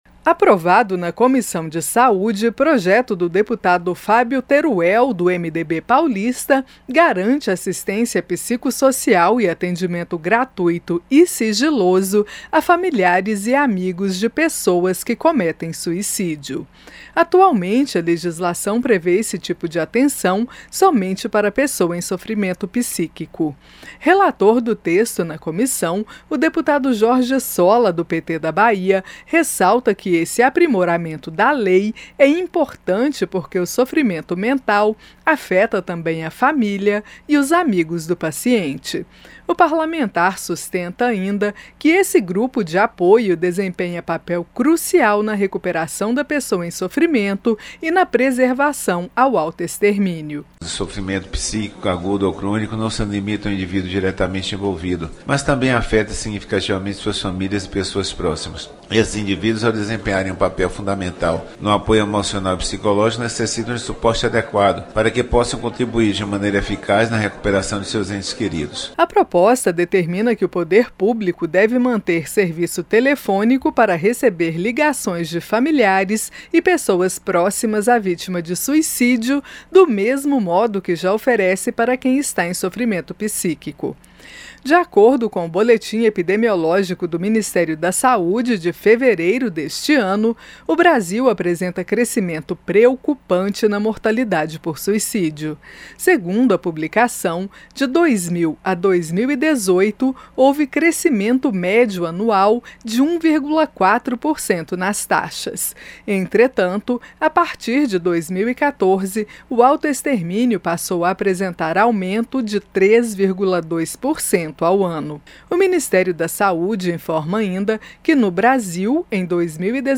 PROPOSTA QUE PREVÊ APOIO A FAMILIARES E AMIGOS DE VÍTIMAS DE SUICÍDIO FOI APROVADA EM COMISSÃO DA CÂMARA. SAIBA OS DETALHES COM A REPÓRTER